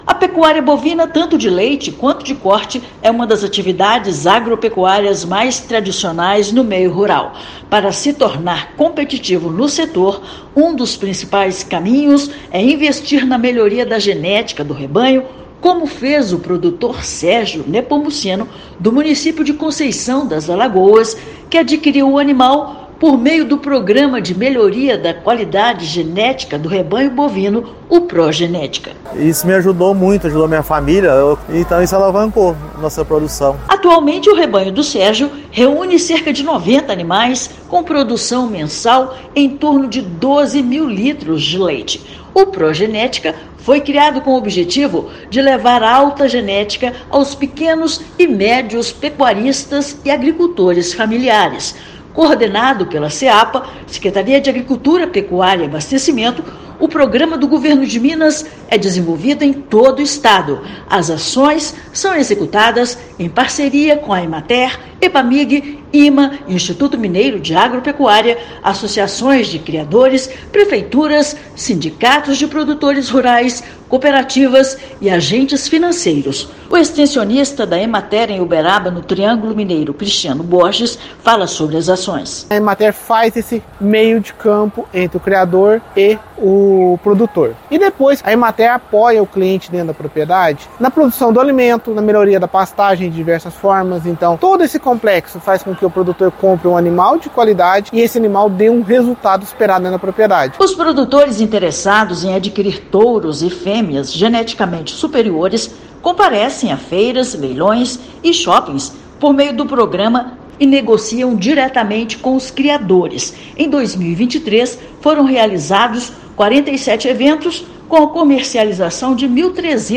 Por meio do Pró-genética, animais de alto padrão são disponibilizados aos criadores em eventos pecuários realizados nos municípios. Ouça matéria de rádio.